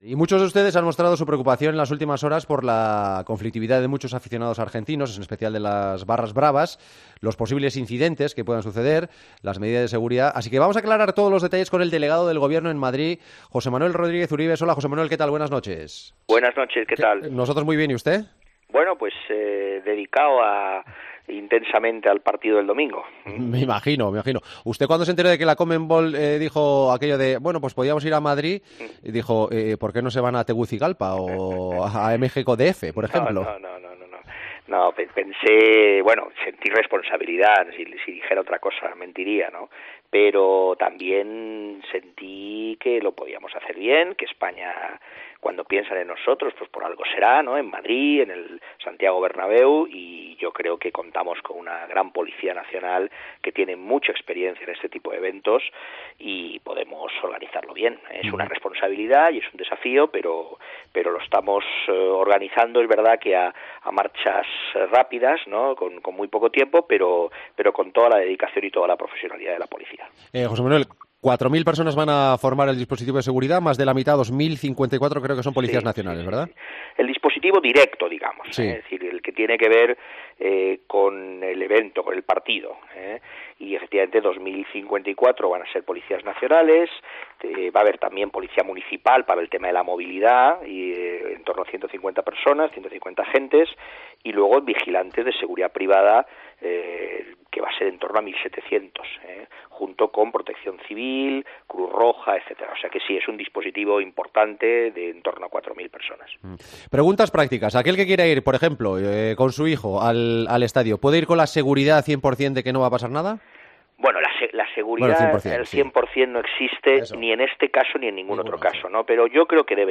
AUDIO: Hablamos con el delegado del Gobierno en la Comunidad de Madrid, José Manuel Rodríguez Uribes, sobre el operativo de seguridad para el River-Boca.